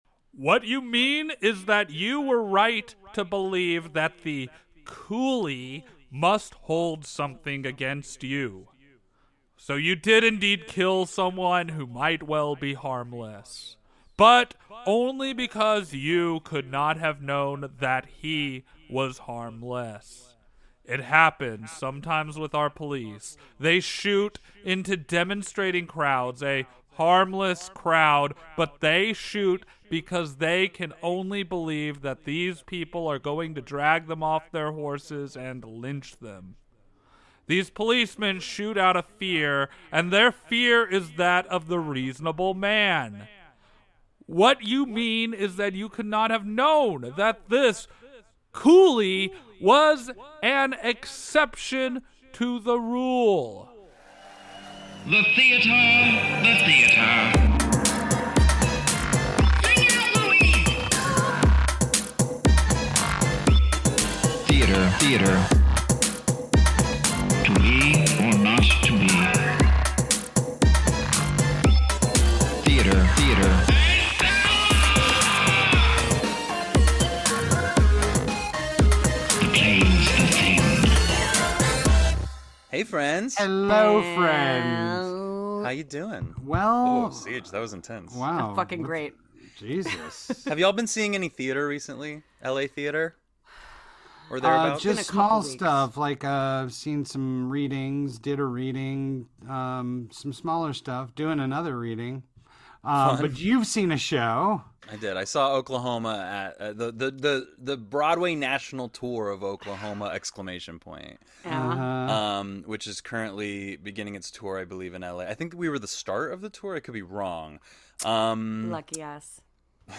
Special guest